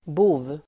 Uttal: [bo:v]